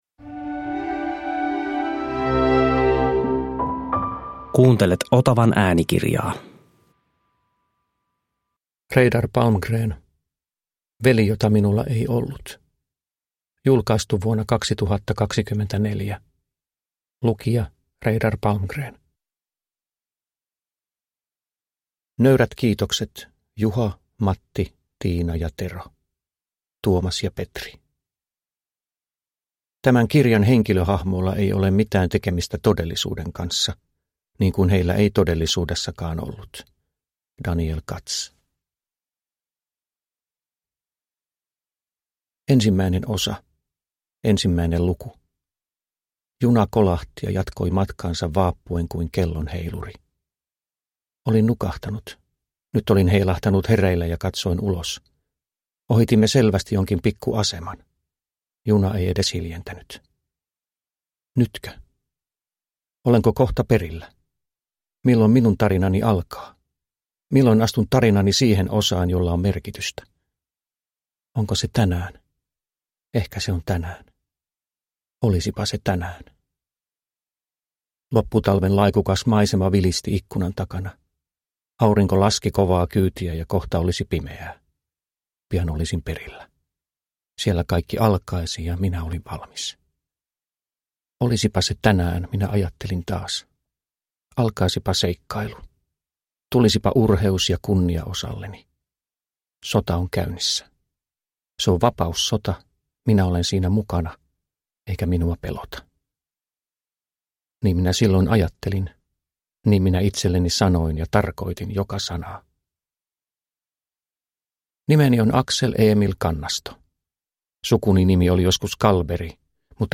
Veli jota minulla ei ollut (ljudbok) av Reidar Palmgren